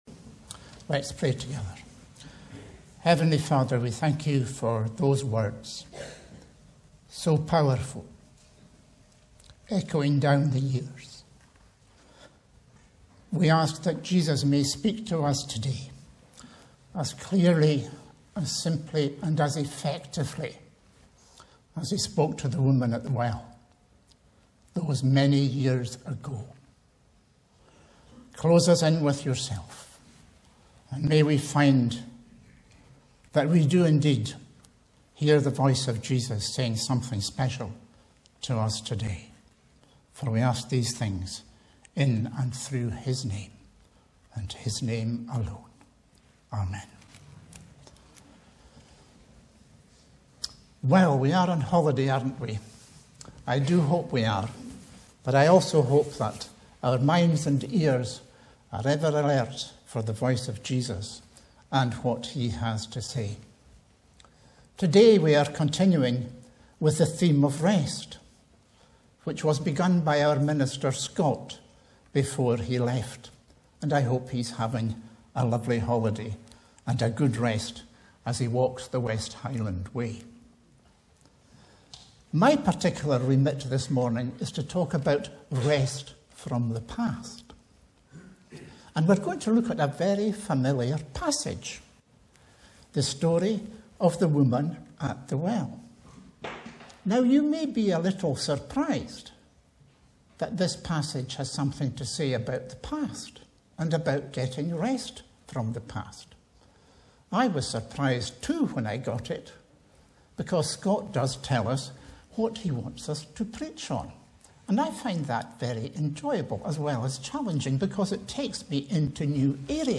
Sermons
Bible references: john 4:1-26 Location: Brightons Parish Church